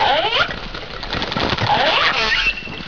All cetaceans are very vocal and use a series of sounds to produce ocean songs for communicating with their pod.
Whale Sounds and Songs
whale-2-sounds.wav